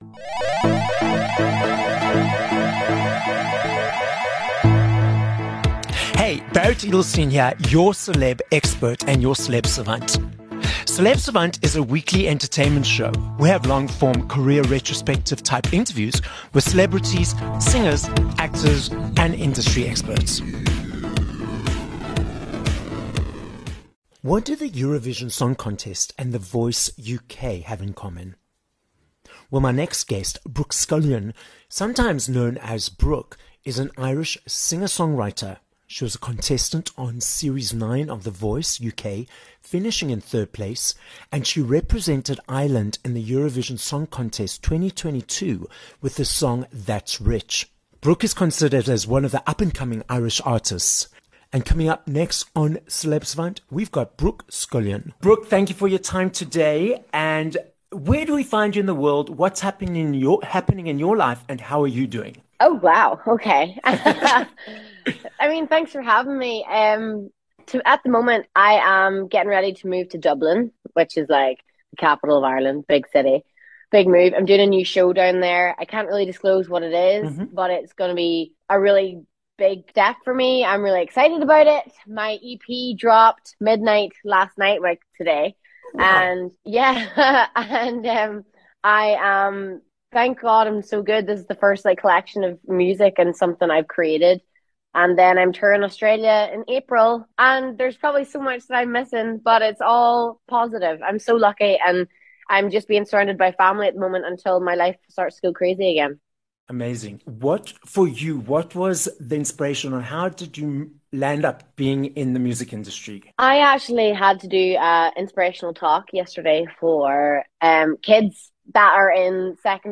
2 Dec Interview with Brooke Scullion